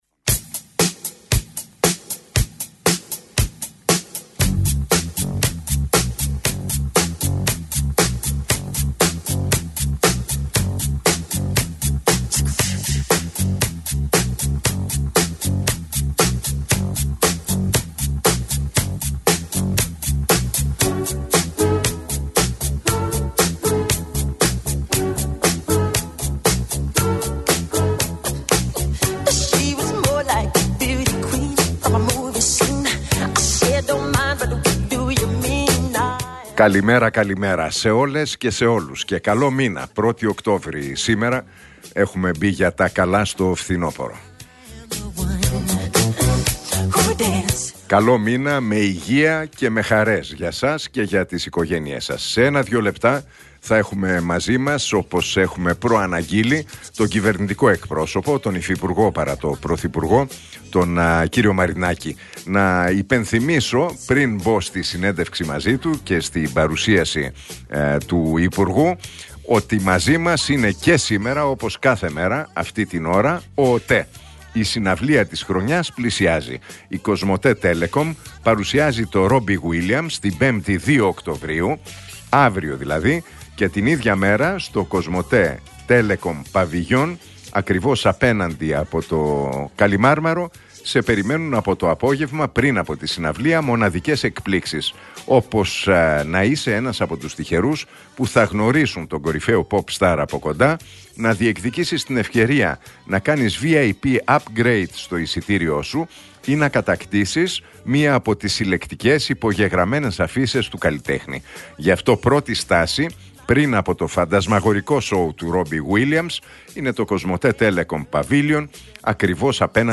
Ακούστε την εκπομπή του Νίκου Χατζηνικολάου στον ραδιοφωνικό σταθμό RealFm 97,8, την Τετάρτη 1 Οκτώβρη 2025.